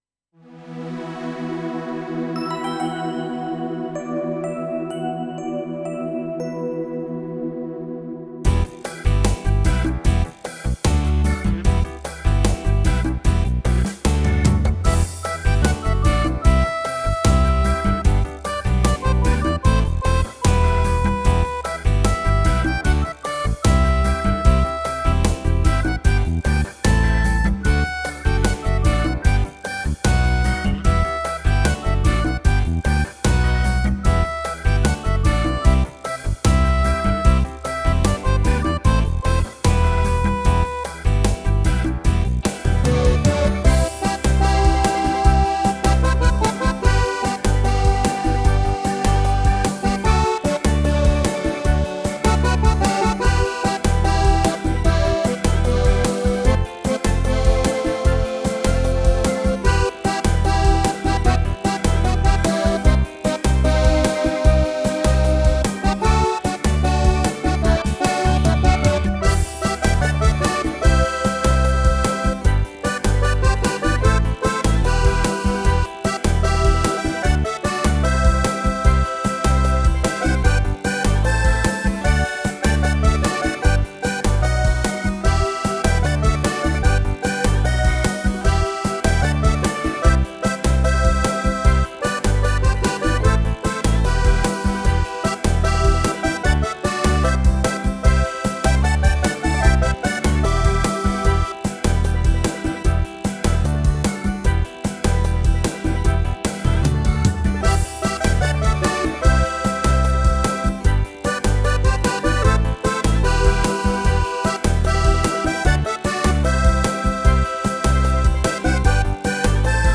Live Performance